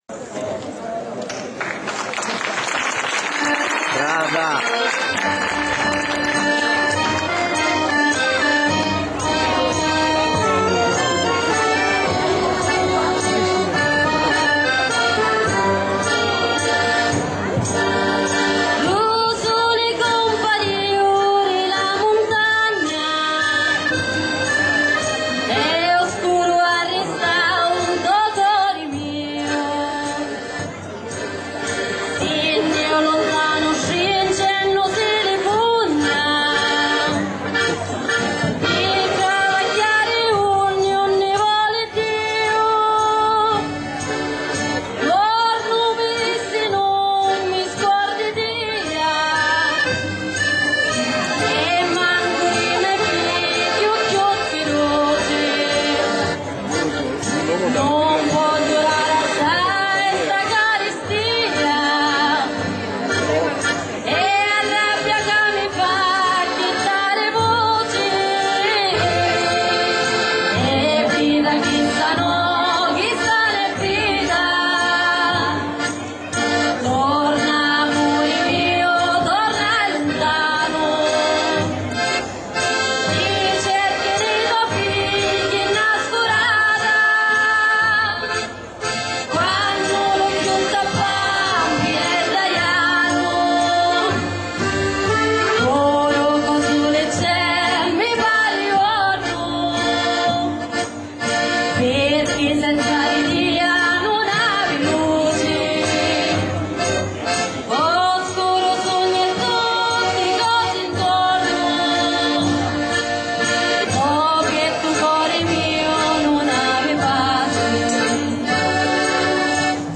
A-ttia luntanu"( Brano cantato dal gruppo Amastra - Testo) -  W la Sagra di Finali -  Diversi a Tusa -  W i Ruggeri,- A festa ru ddappu ( brano cantato dal gruppo Amastra - il testo è stato scritto da Mario De Caro) - Molte altre canzoni non sono state messe in cantiere perché i rapporti si sono interrotti nel 1996.